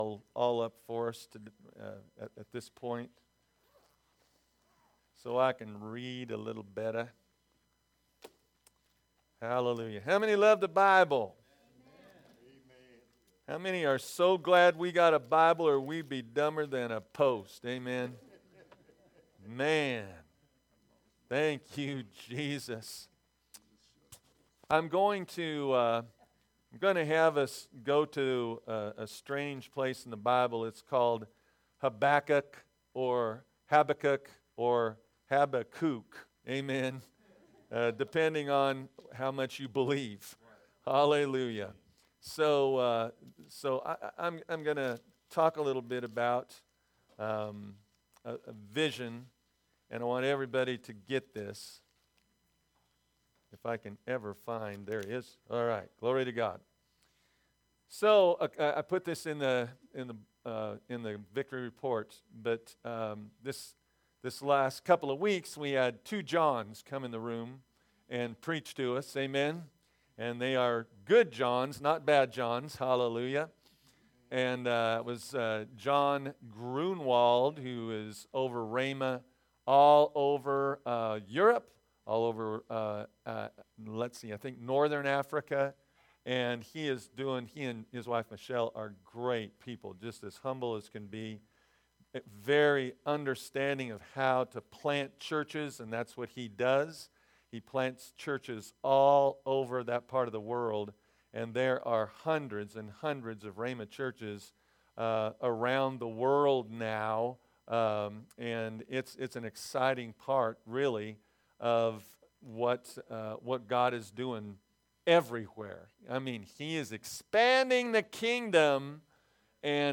Sermons | Victory Christian Fellowship